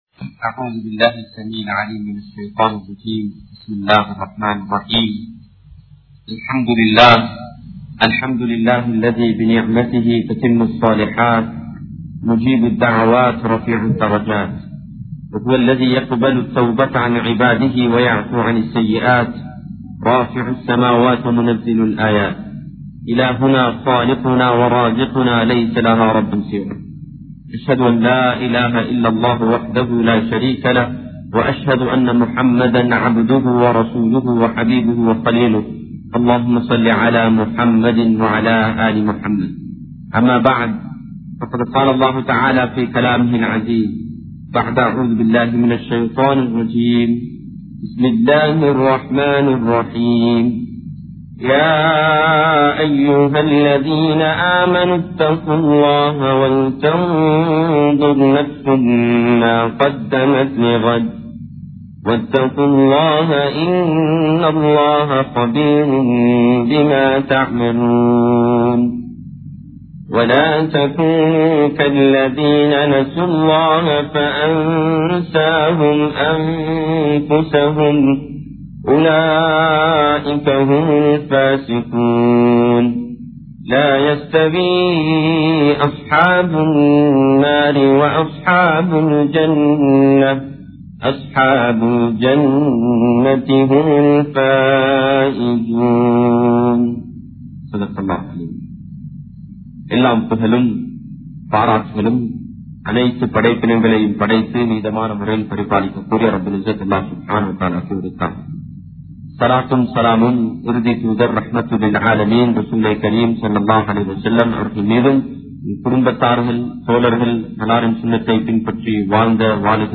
Purinthunarvu! (புரிந்துணர்வு!) | Audio Bayans | All Ceylon Muslim Youth Community | Addalaichenai